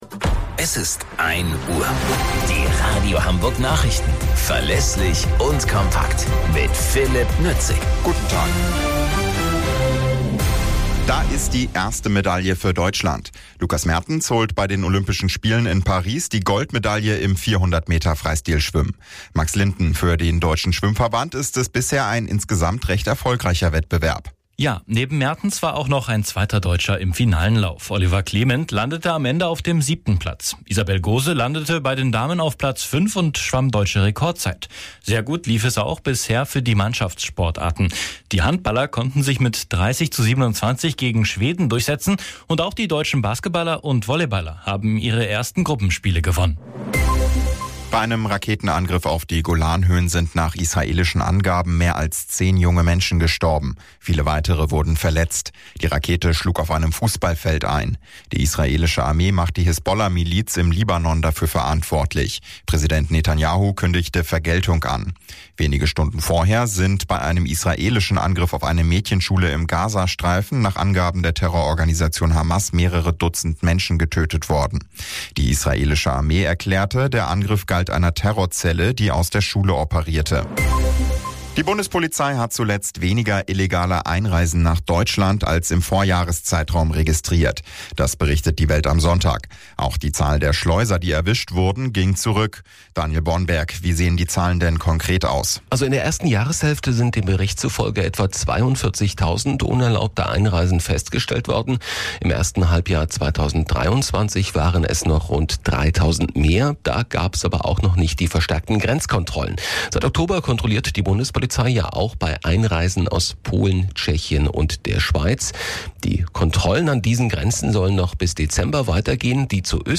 Radio Hamburg Nachrichten vom 25.08.2024 um 03 Uhr - 25.08.2024